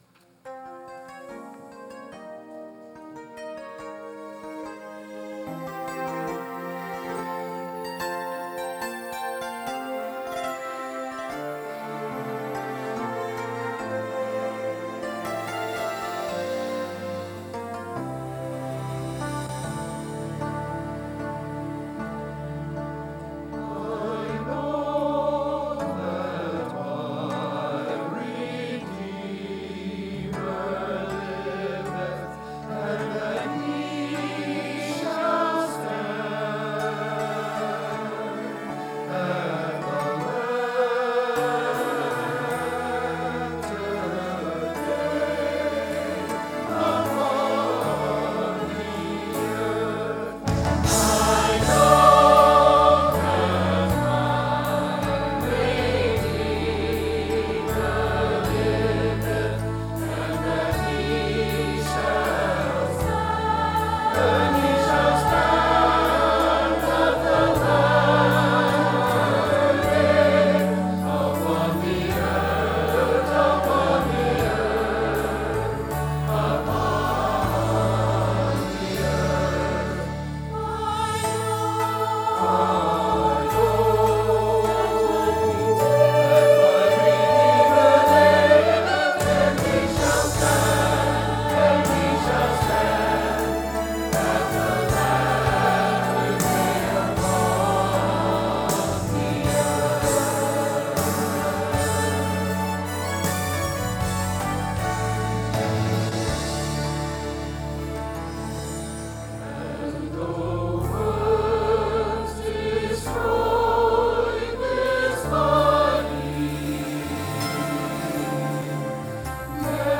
Sunday Morning Music
Celebration Choir - I Know My Redeemer Liveth